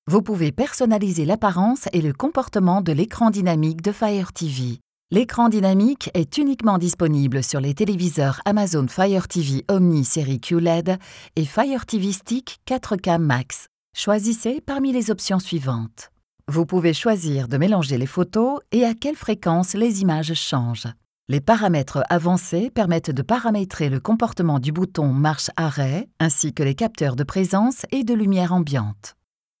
Chaude, Profonde, Fiable, Mature, Corporative
Corporate
Vidéo explicative